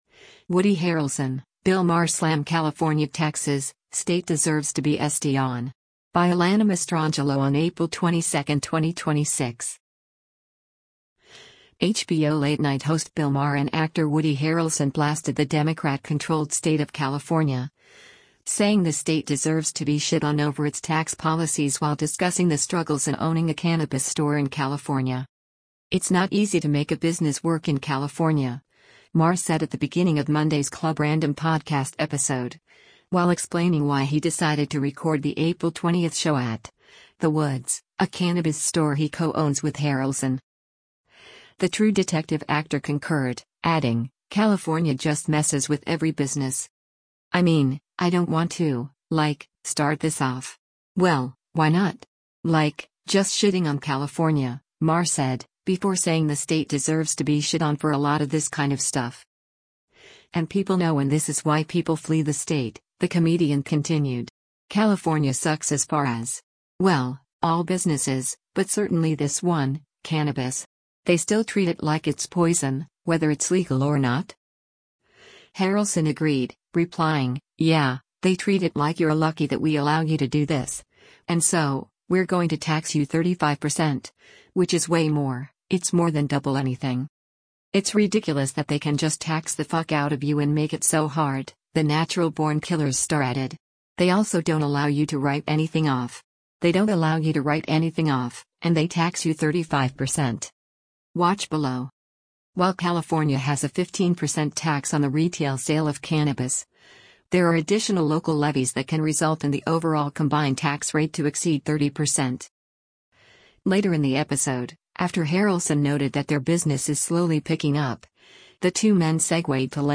“It’s not easy to make a business work in California,” Maher said at the beginning of Monday’s Club Random podcast episode, while explaining why he decided to record the April 20 show at, The Woods, a cannabis store he co-owns with Harrelson.